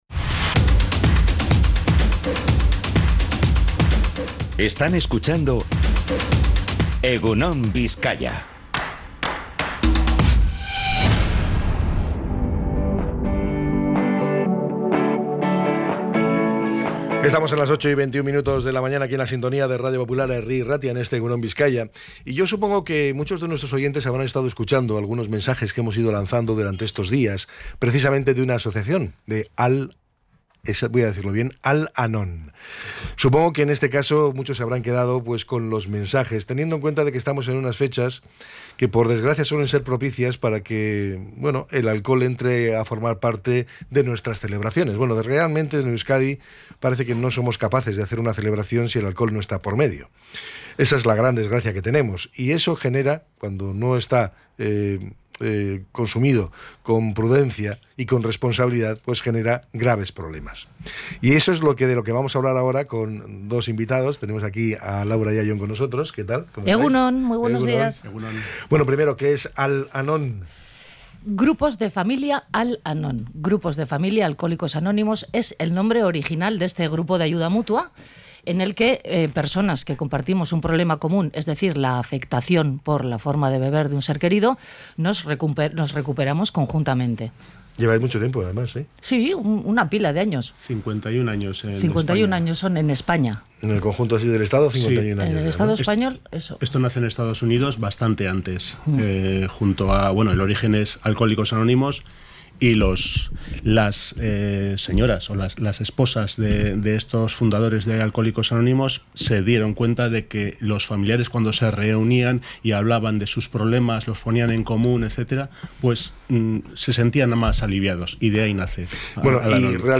Entrevista a la Asociación Al-Anon de Bizkaia